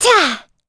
Gremory-Vox_Attack2.wav